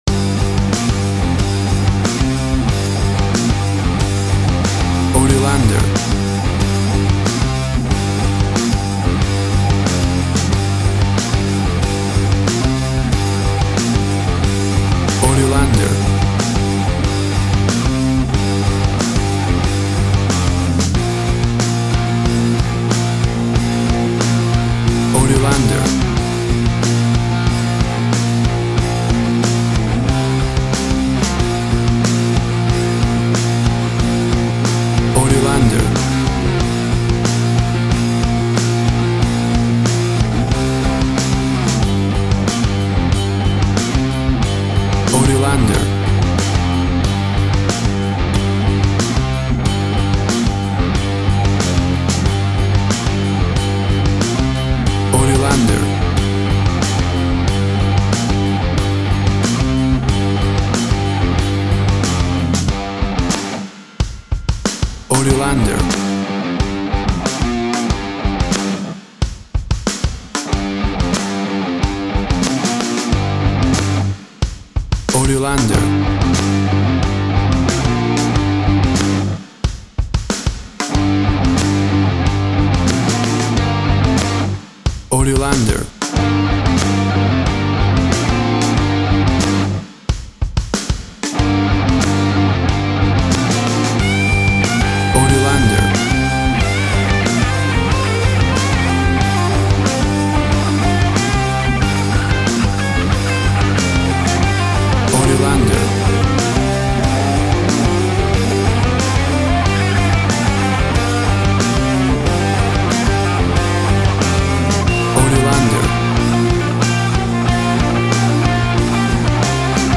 1970´s Classic heavy metal Rock.
Tempo (BPM) 93